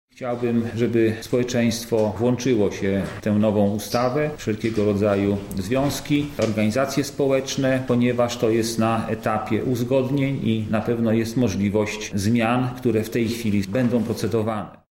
-dodaje senator